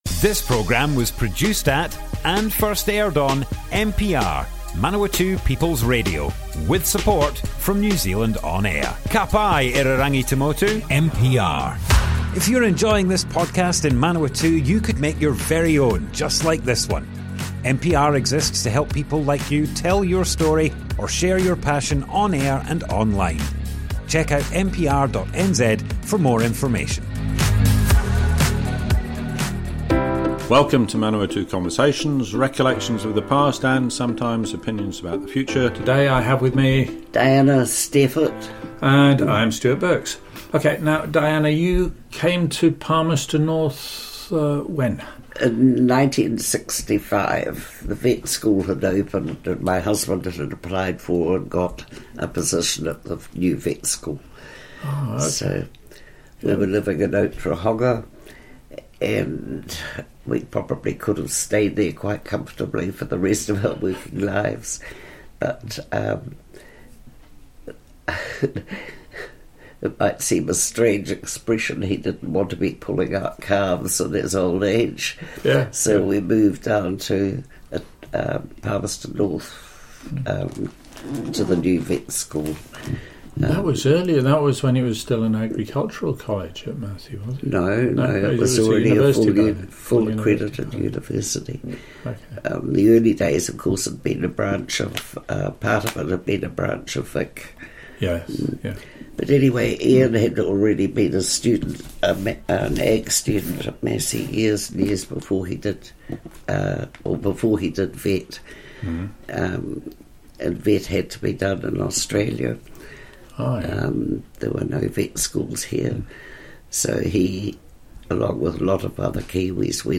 Manawatu Conversations Object type Audio More Info → Description Broadcast on Manawatu People's Radio, 24th January 2023. Part 1 of 2 To Palmerston North in 1965, husband at Vet School.
oral history